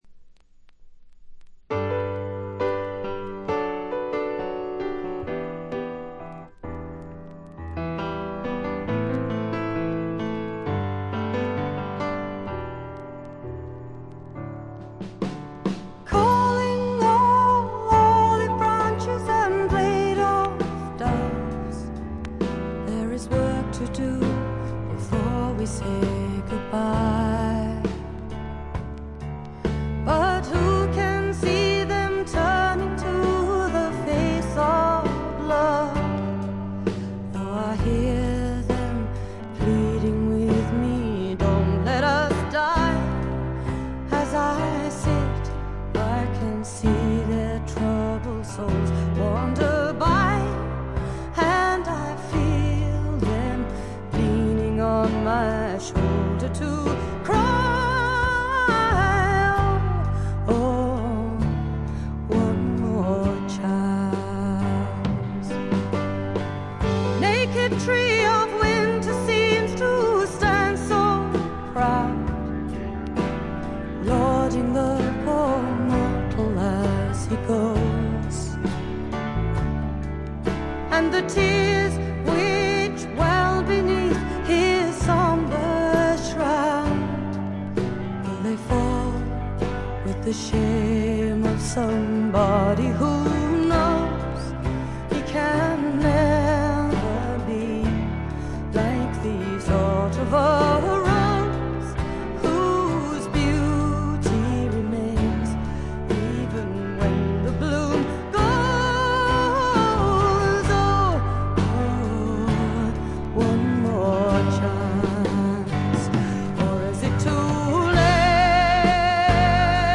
チリプチ多めですが、大きなノイズはありません。
試聴曲は現品からの取り込み音源です。
Recorded and mixed at Olympic Sound Studios, London